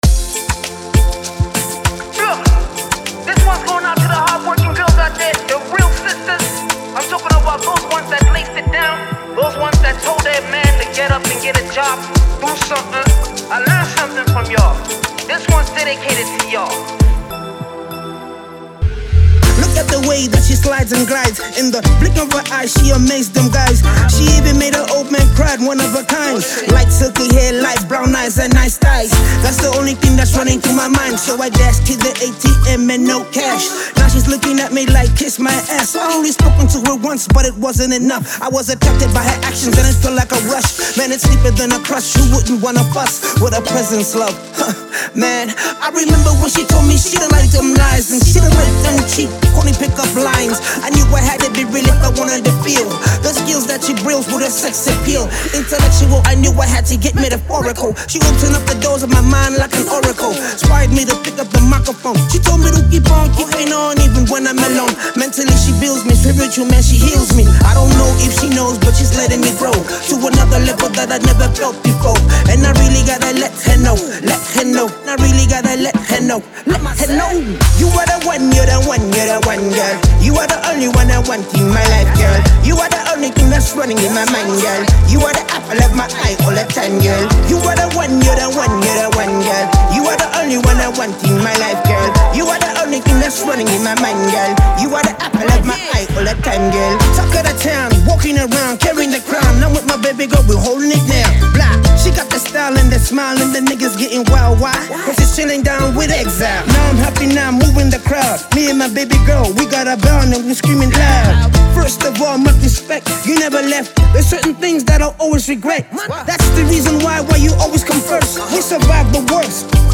a blend of dancehall, hip hop, and Afro-fusion